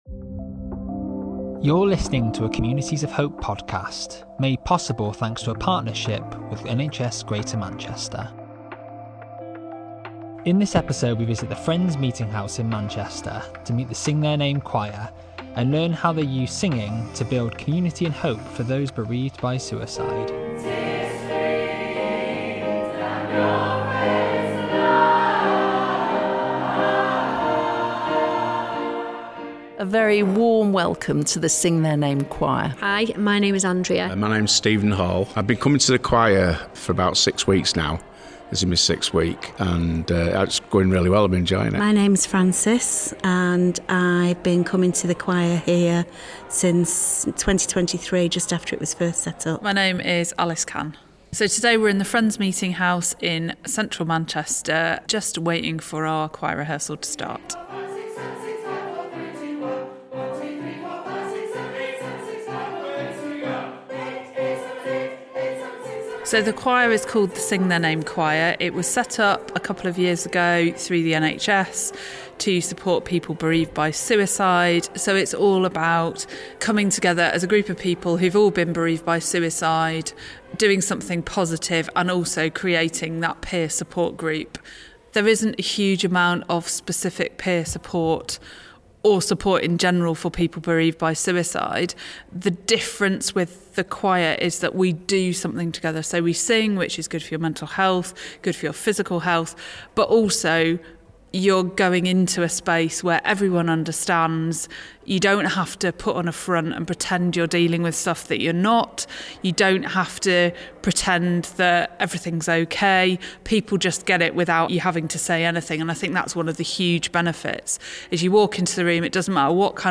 In this episode, we visit a special choir providing hope, friendship and support for people in Greater Manchester who have lost someone they dearly love to suicide.
We speak to various members about how they got involved and the incredible impact the choir has had on their mental health.